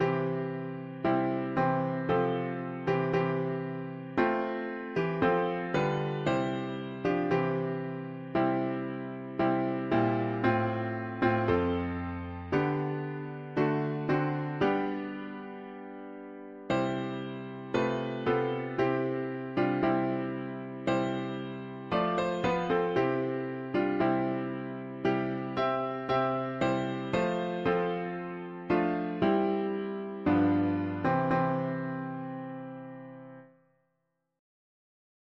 Earth ha… english theist 4part chords
Key: C major